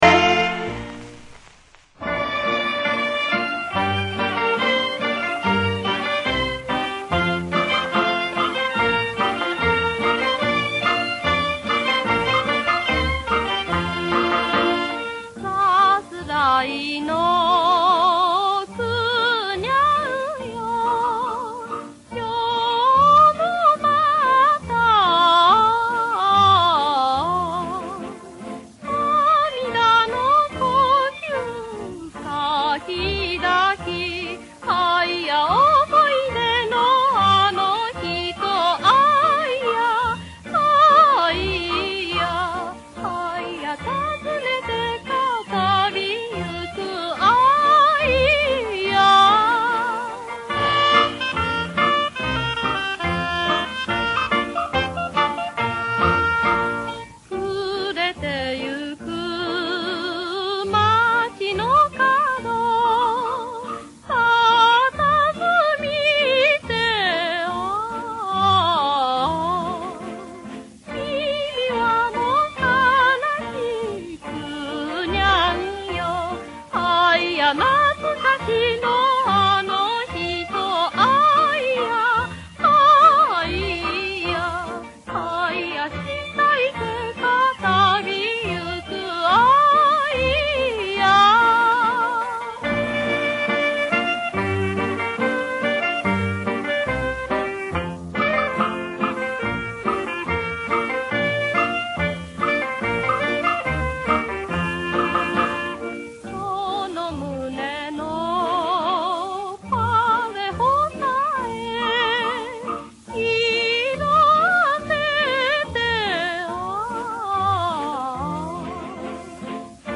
介绍几位日本老牌歌星翻唱的中国歌曲，感受那种带有东洋风韵的熟悉旋律。